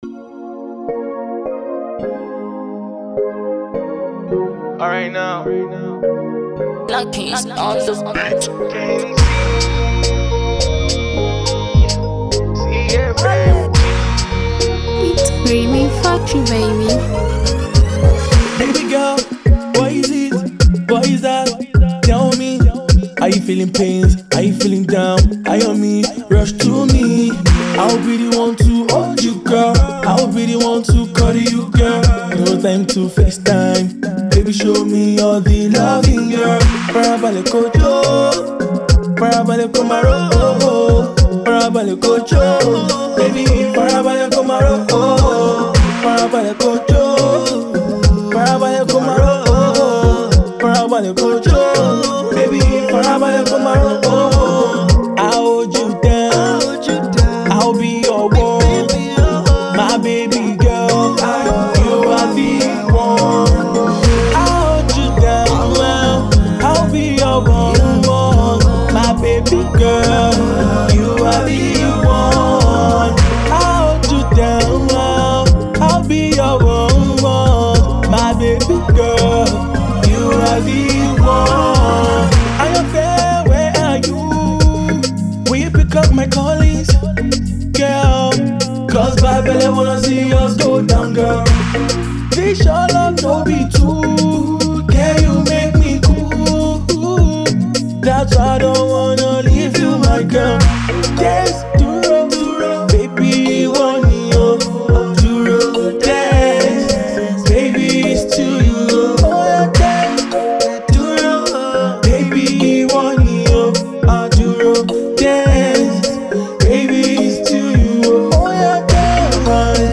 new hit banger
A romantic and lovely song